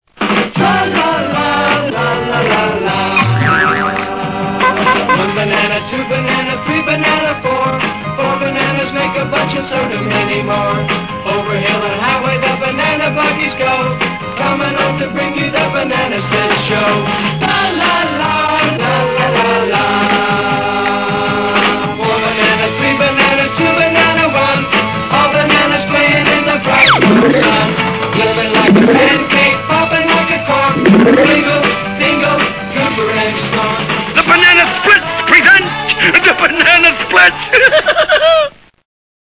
The Banana Split theme music.